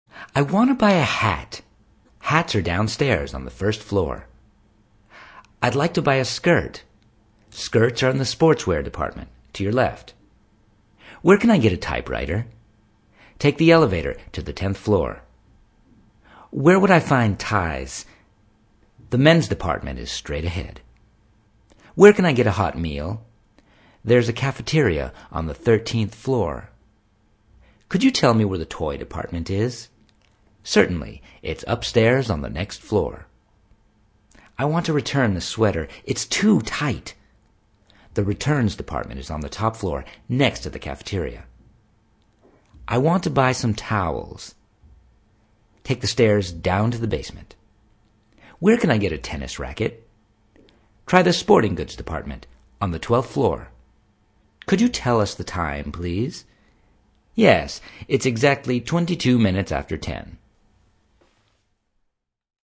Hi Classes – below are recordings of myself reading the dialogs you have chosen. Listen to the examples and practice with the stress I use.